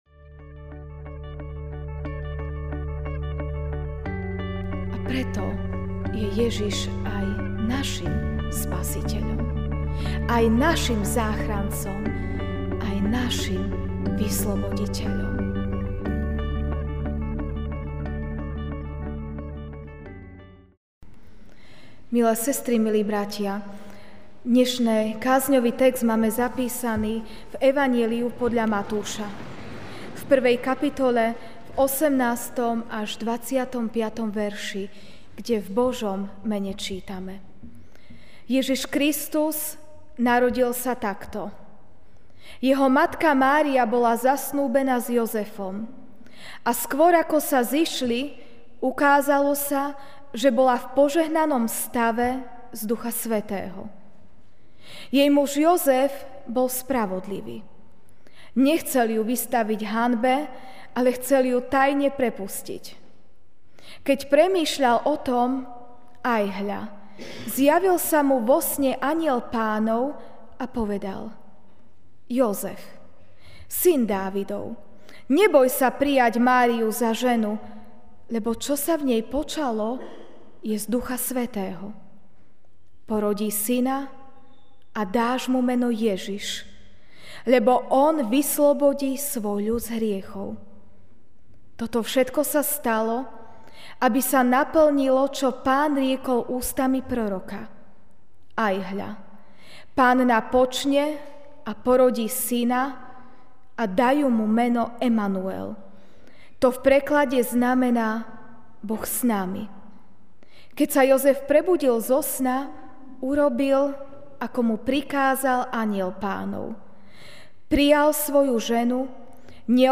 Ranná kázeň: Ježiš je pre nás...